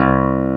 CLAV2SFTC2.wav